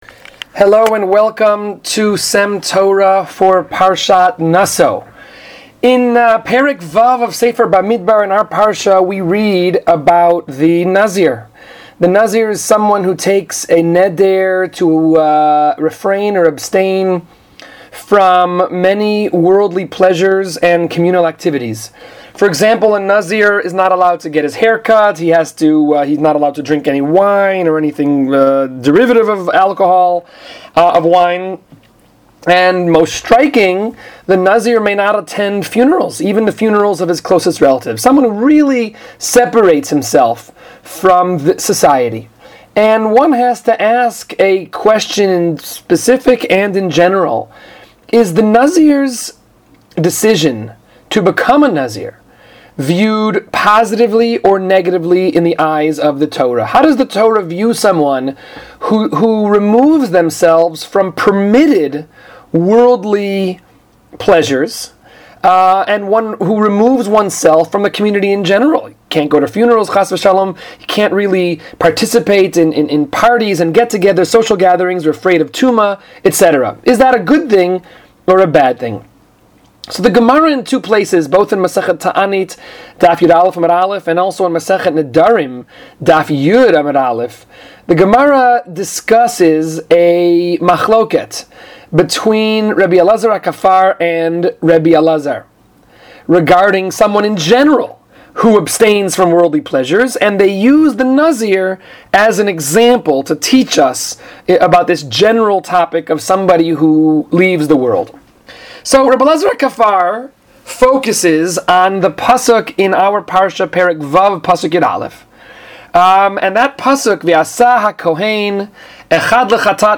S.E.M. Torah is a series of brief divrei Torah delivered by various members of the faculty of Sha’alvim for Women.